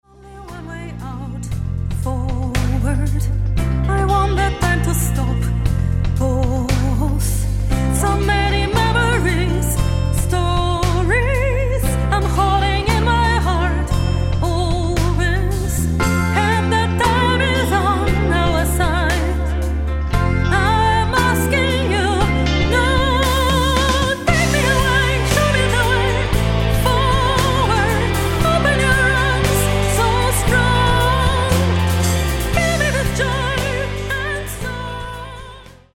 Bass
Drums
Guitar
Keyboards
Backup vocals
Mastered at Abbey Road Studios, London